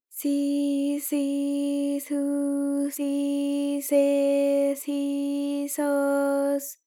ALYS-DB-001-JPN - First Japanese UTAU vocal library of ALYS.
si_si_su_si_se_si_so_s.wav